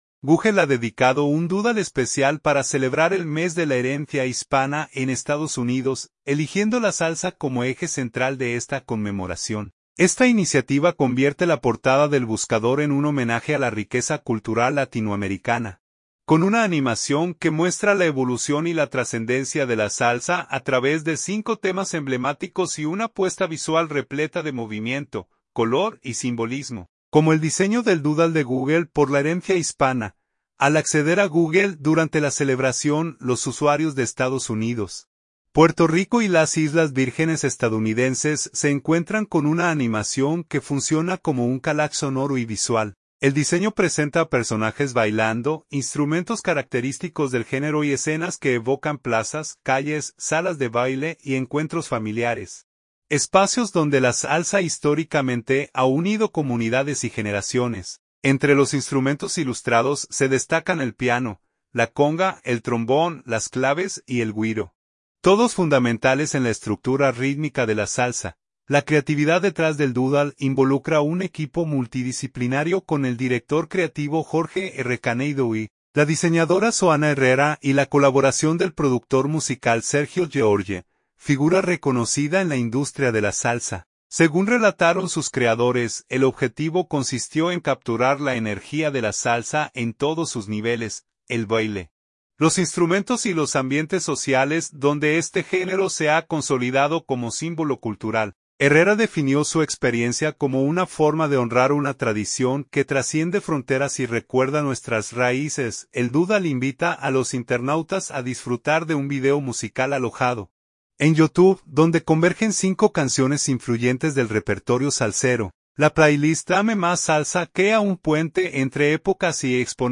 video musical